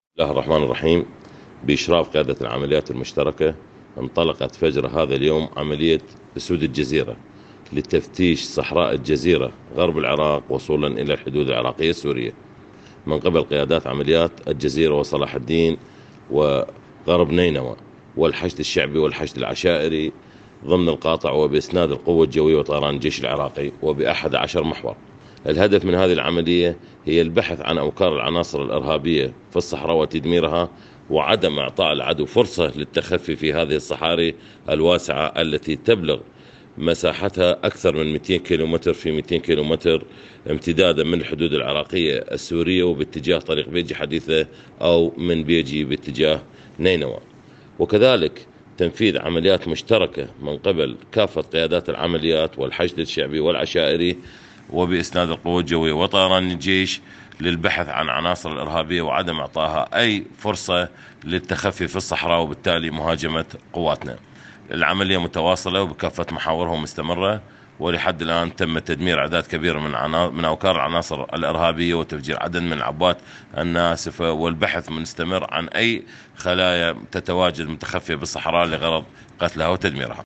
تصريح قائد عمليات الجزيرة
اللواء الركن قاسم محمد صالح حول عملية اسود الجزيرة التي انطلقت صباح اليوم لملاحقة فلول د١١عش الاجرامية.